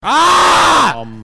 AAAAAAAAAHHHHHHHHHHHHHH
aaaaaaaaahhhhhhhhhhhhhh.mp3